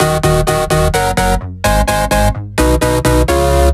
VFH3 128BPM Resistance Melody 1.wav